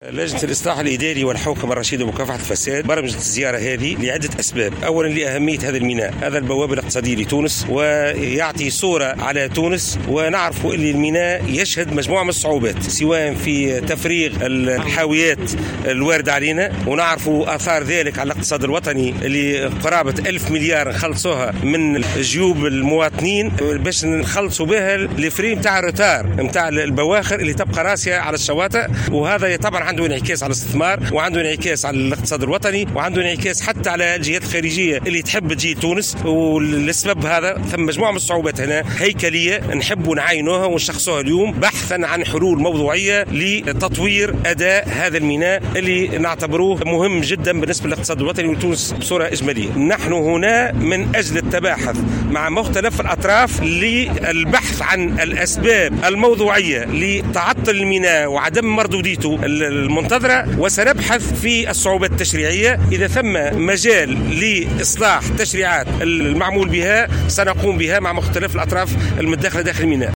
وفي تصريح للجوهرة أف أم ، قال رئيس اللجنة بدر الدين القمودي إن ميناء رادس يمثّل البوابة الإقتصادية لتونس وهو يشهد عديد الصعوبات مما يؤثر على الإقتصاد الوطني .